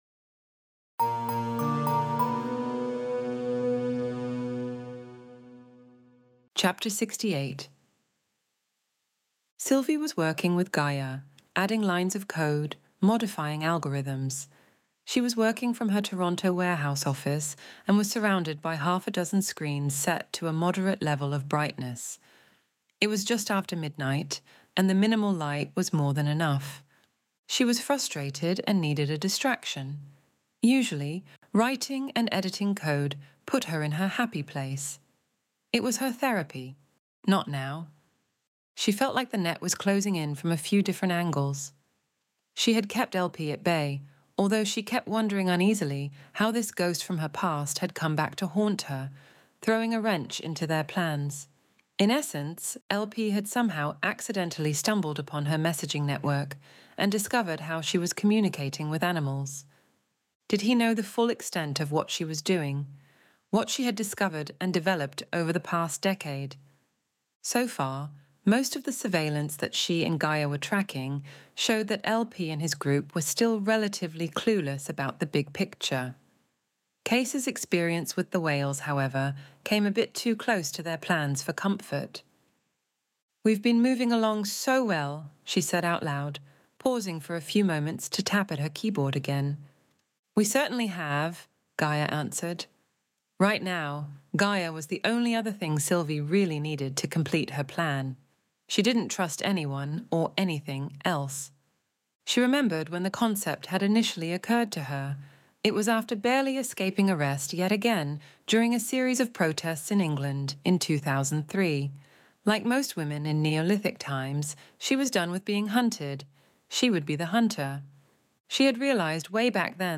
Extinction Event Audiobook Chapter 68